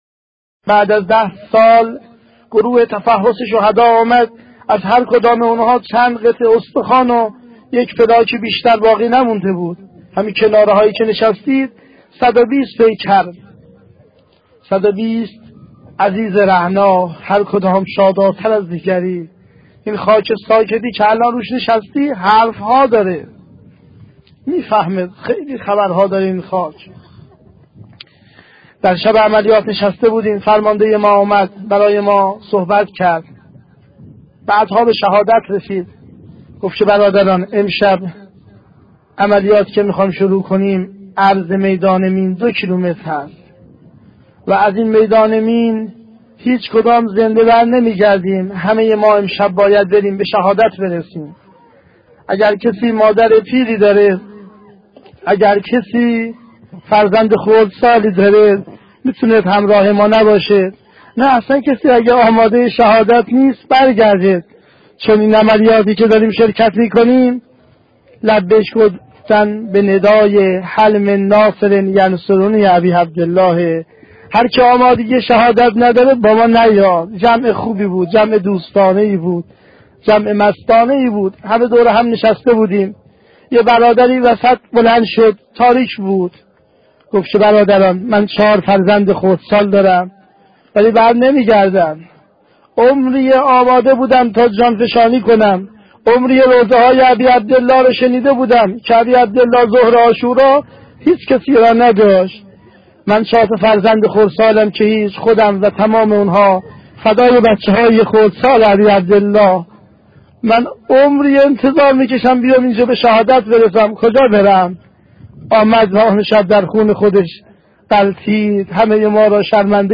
صوت روایتگری
ravayatgari61.mp3